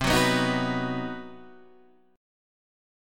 C Minor Major 7th Flat 5th
CmM7b5 chord {8 9 9 8 7 7} chord